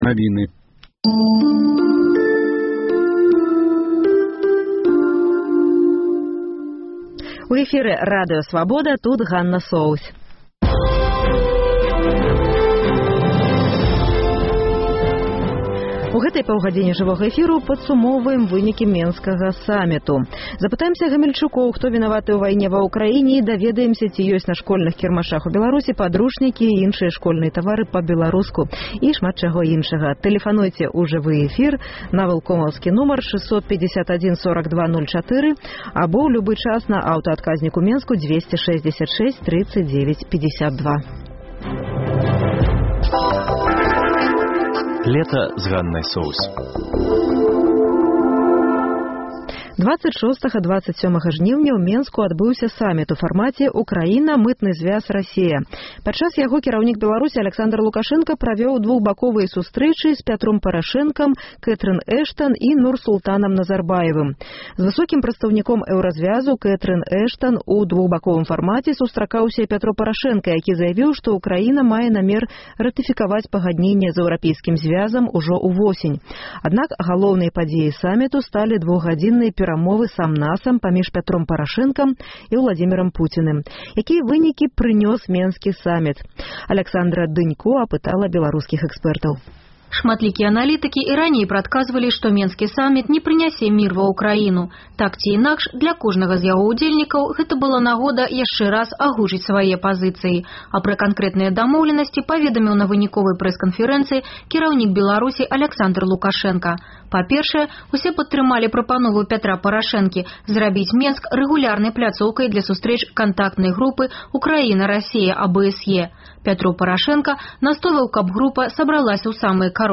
Ці ёсць на школьных кірмашах сшыткі і дзёньнікі па-беларуску? Рэпартаж Свабоды.